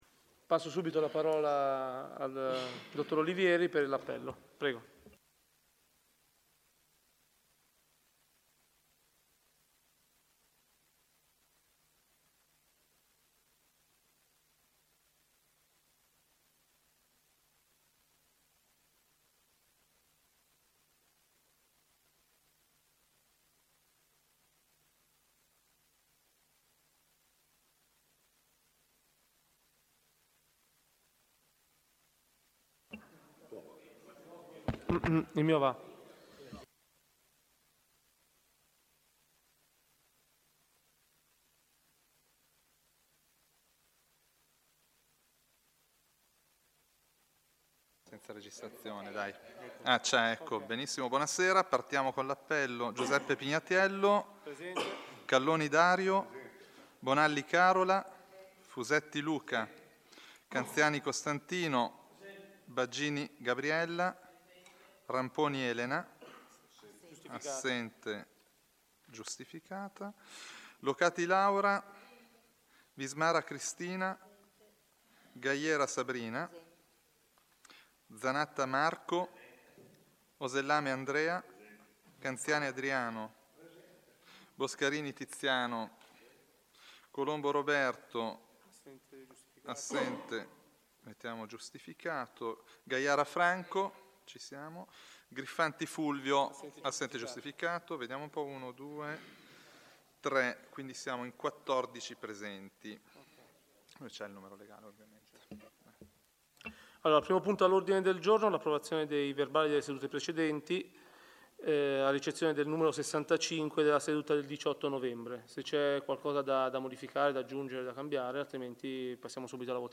CONSIGLI COMUNALI ANNO 2014
In questa sezione sarà possibile accedere alle registrazioni dei Consigli Comunali relativi all'anno 2014.